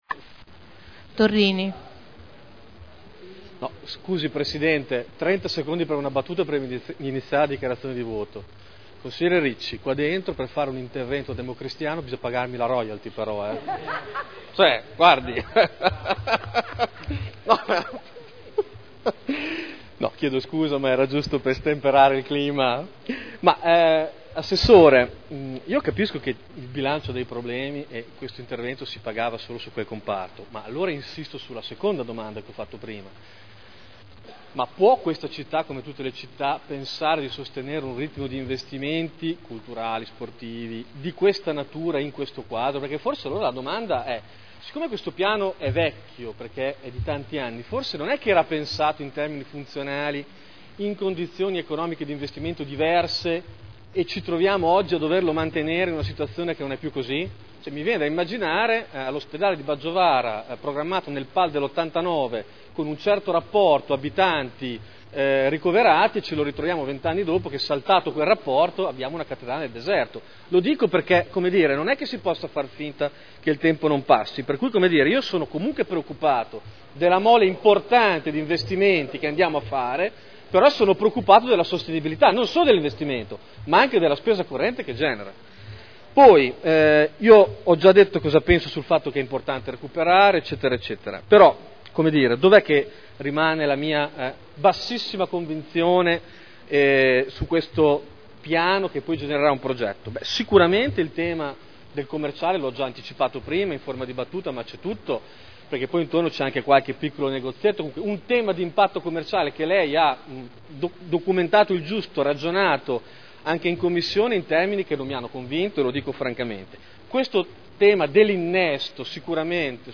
Seduta del 21/12/2009. Dichiarazione di voto.